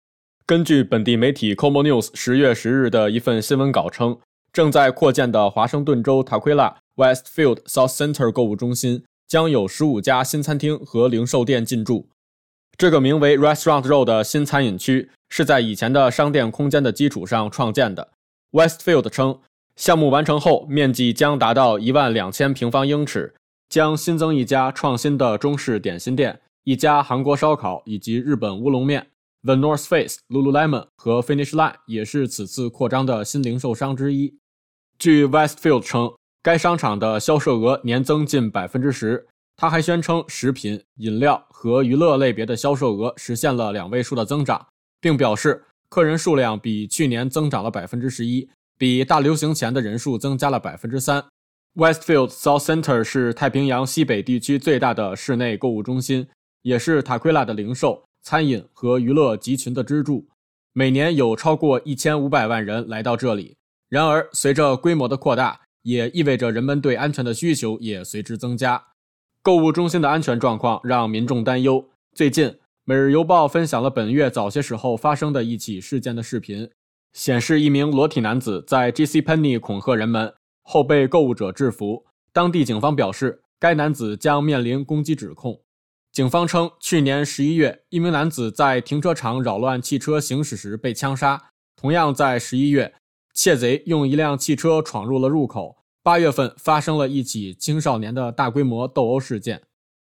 每日新聞 Westfield Southcenter 購物中心擴展引公共安全擔憂（10/11/23） Play Episode Pause Episode Mute/Unmute Episode Rewind 10 Seconds 1x Fast Forward 30 seconds 00:00 / 00:01:41 Subscribe Share RSS Feed Share Link Embed